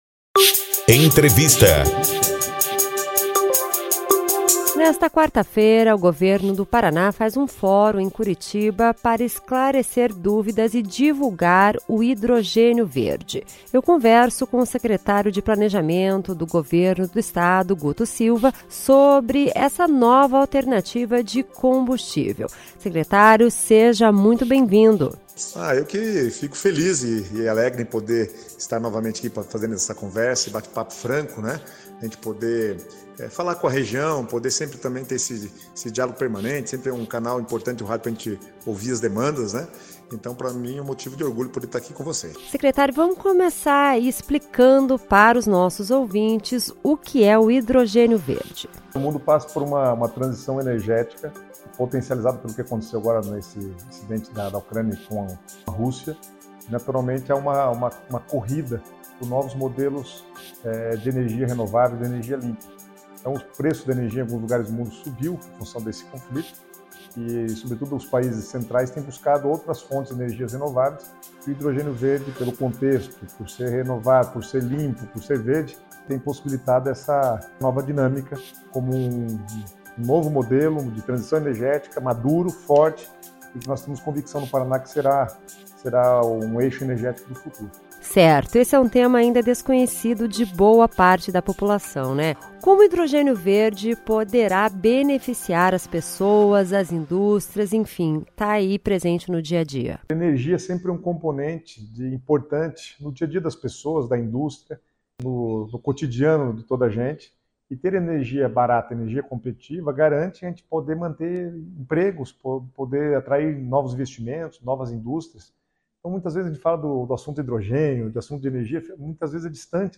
Entrevista com o Secretário de Planejamento do Governo do Estado, Guto Silva, sobre o Hidrogênio Verde, uma nova alternativa de combustível.